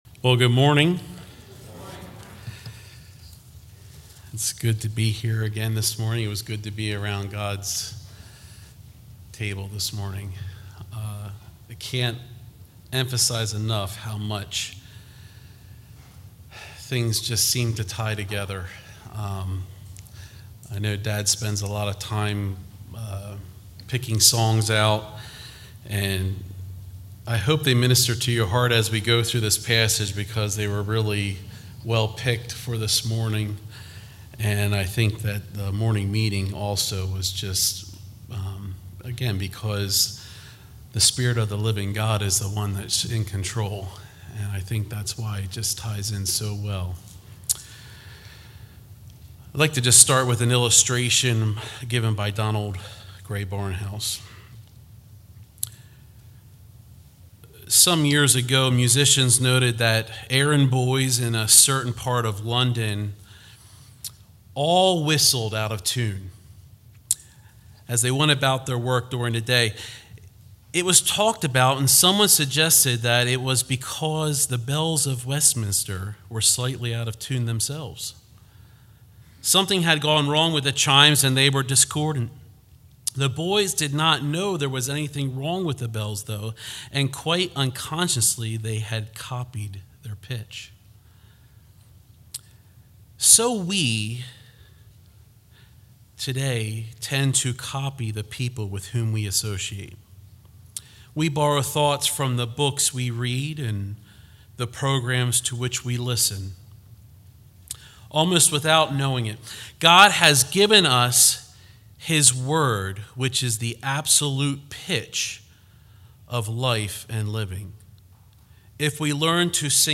All Sermons 1 Peter 1:13-21